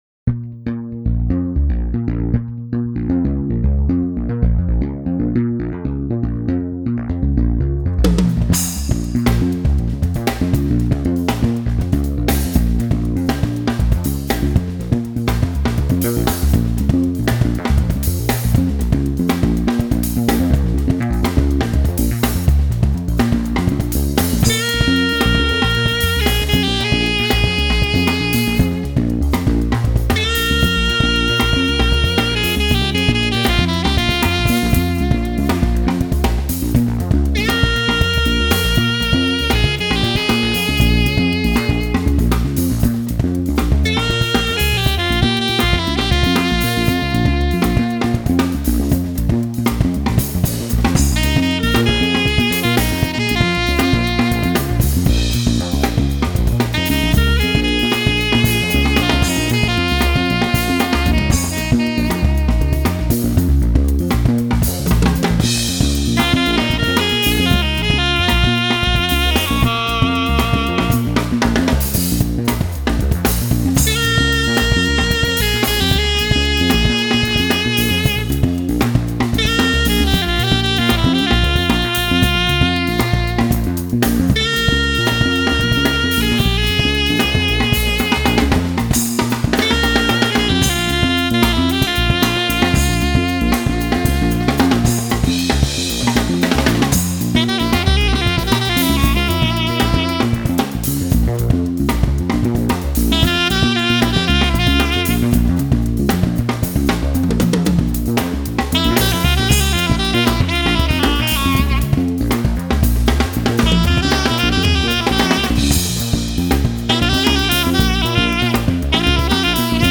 kalimba
sax, bass and balafon.